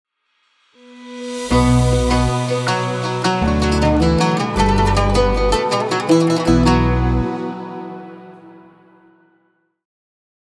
مستوحاة من مقام السيكا لتعكس تاريخ المدينة المنورة
الإشعارات والتنبيهاتنغمات قصيرة تُستخدم في الحرم الجامعي للتنبيهات والإعلانات
Oud version 1.wav